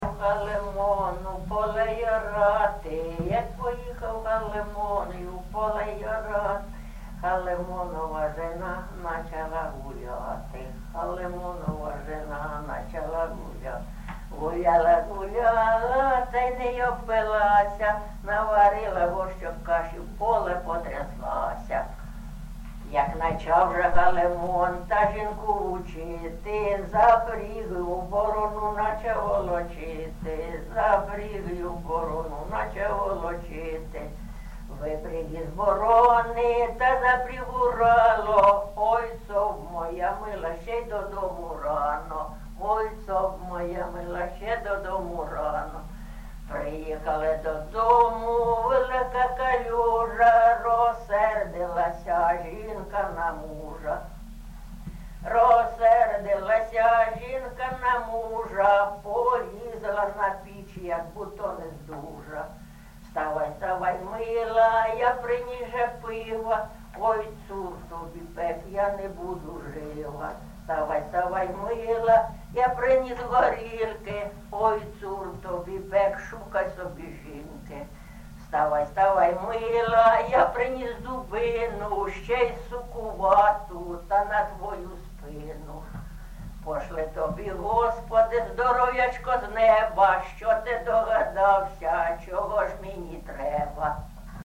ЖанрПісні з особистого та родинного життя, Жартівливі
Місце записус. Софіївка, Краматорський район, Донецька обл., Україна, Слобожанщина